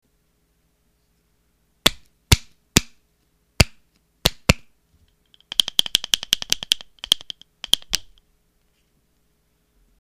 紙なので今ひとつスカッとした音が出ない上、折り目がすぐにへたれてしまうのですが、手軽さと、紙でもこれくらいの音が出ると思えば許せるかな、といったところです。
試聴　前半は写真左の楽器、後半は右の柄つきの楽器の音です。